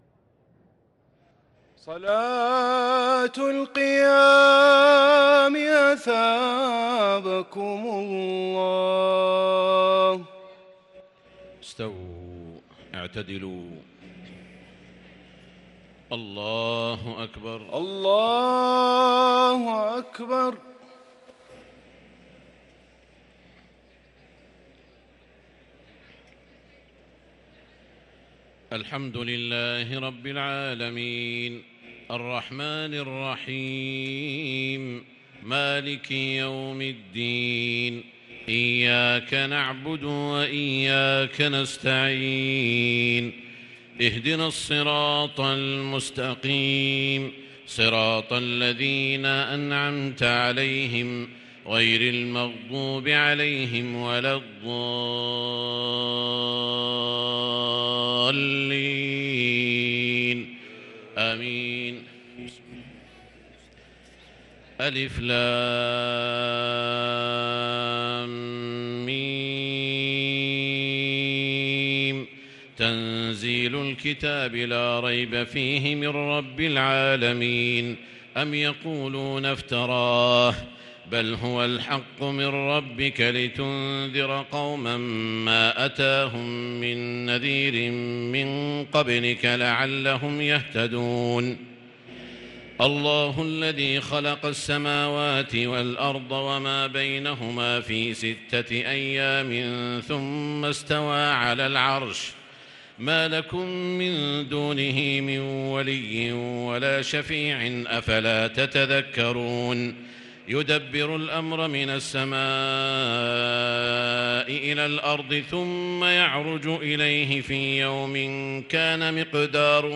صلاة التراويح ليلة 24 رمضان 1443 للقارئ سعود الشريم - الثلاث التسليمات الاولى صلاة التهجد